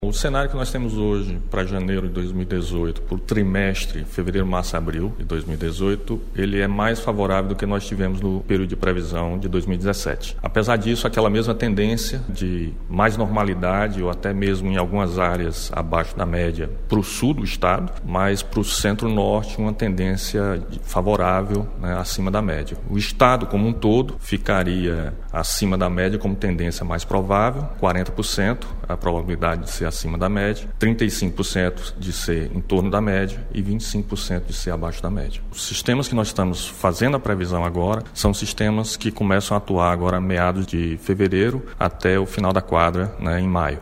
Nesta segunda-feira, 22, o governador do Ceará, Camilo Santana concedeu entrevista coletiva no Palácio da Abolição, em Fortaleza, para comunicar o prognóstico de chuvas no estado para os meses de fevereiro, março e abril.
Confira abaixo o áudio do presidente da Funceme:
Presidente da Funceme, Eduardo Sávio
Presidente-da-Funceme-Eduardo-Sávio.mp3